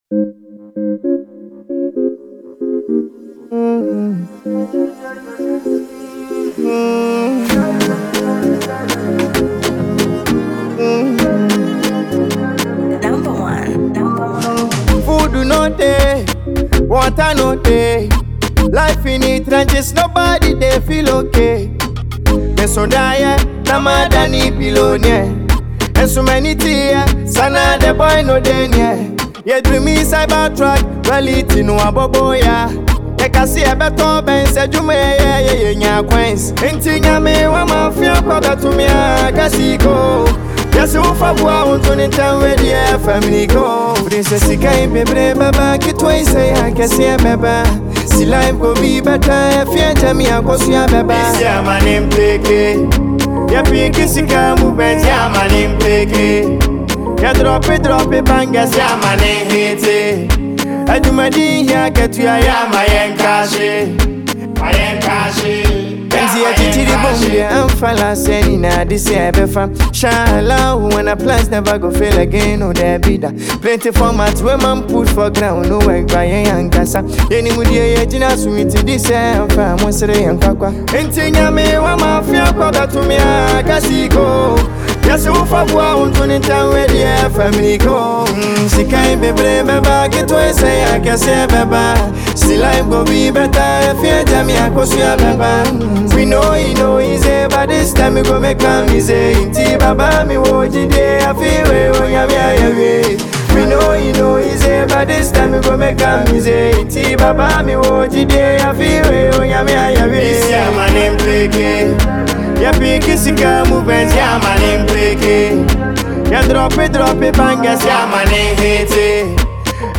Genre: Afrobeats / Inspirational